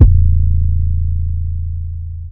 Teck-808 (camewittheposse).wav